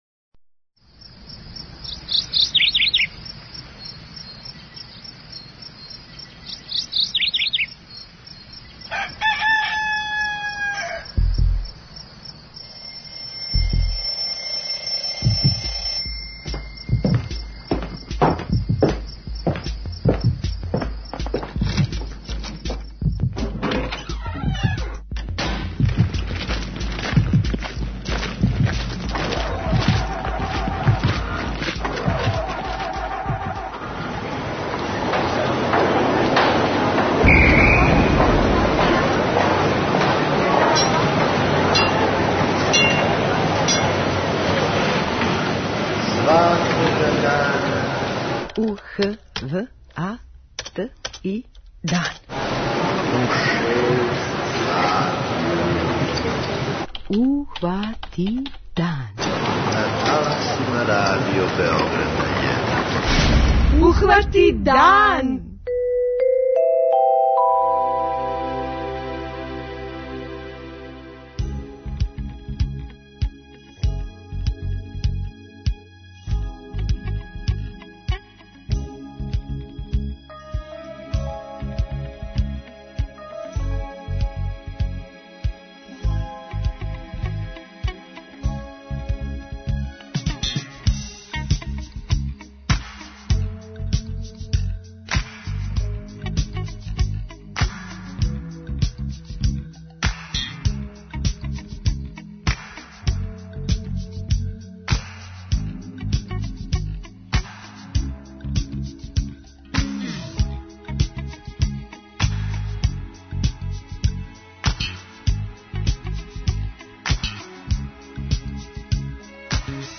преузми : 29.67 MB Ухвати дан Autor: Група аутора Јутарњи програм Радио Београда 1!